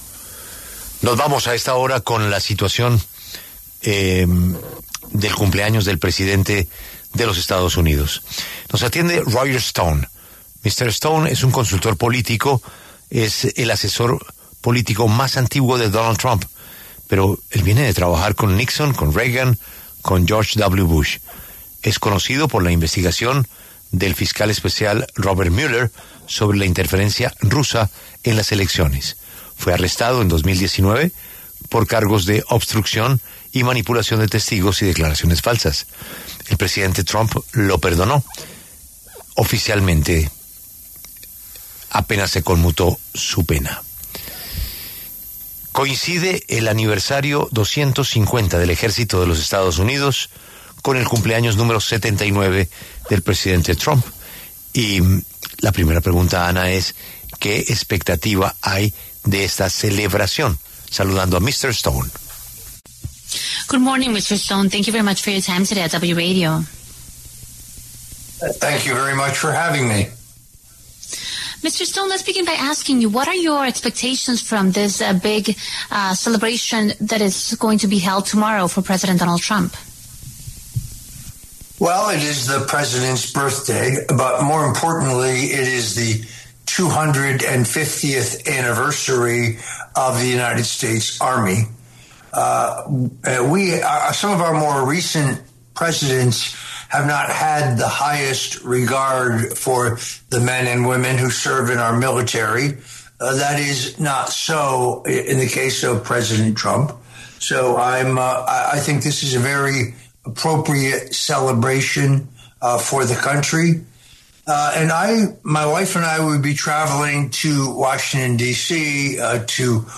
El consultor político estadounidense, Roger Stone, pasó por los micrófonos de La W, con Julio Sánchez Cristo, para hablar sobre las celebraciones planeadas para el próximo sábado 14 de junio, fecha en la cual será el aniversario 250 del ejército de Estados Unidos y el cumpleaños 79 de Donald Trump.